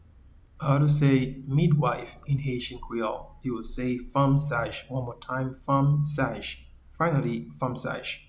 Pronunciation:
Midwife-in-Haitian-Creole-Fanm-saj.mp3